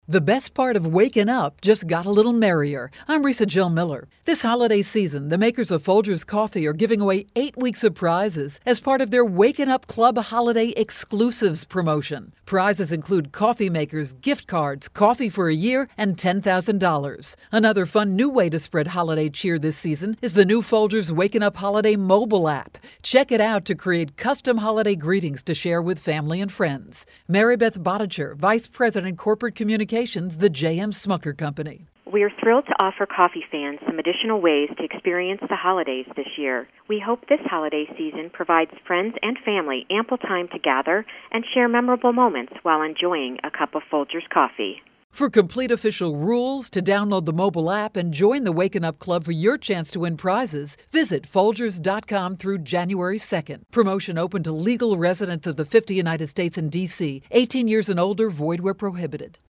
December 3, 2012Posted in: Audio News Release